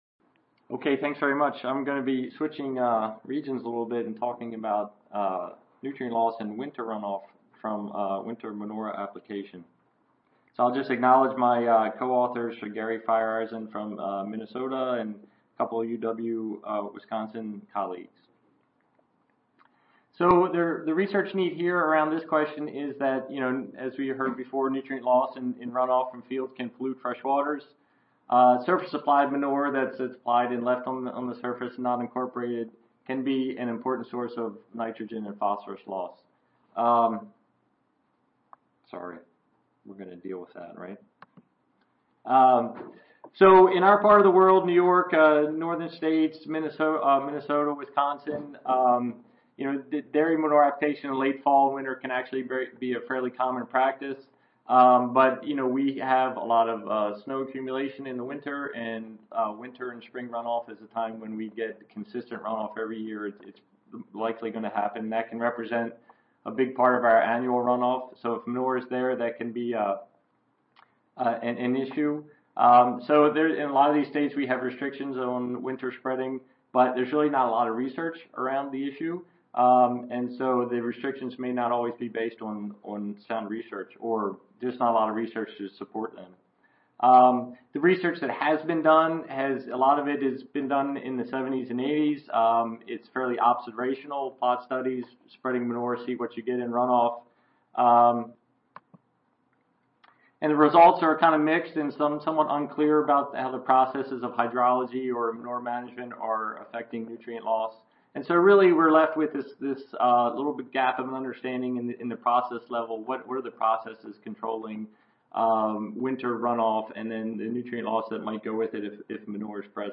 Session: Nutrient Dynamics and Management in Livestock Production Systems Oral (includes student competition) (ASA, CSSA and SSSA International Annual Meetings)
University of Wisconsin-Madison Audio File Recorded Presentation